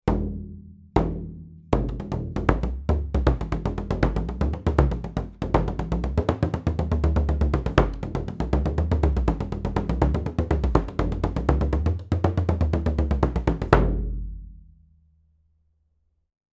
Bodhram
Bodhran – irski boben – ima na eni strani napeto kožo, na drugi pa je odprt.
Na boben se udarja z roko ali paličico.
BODHRAN.mp3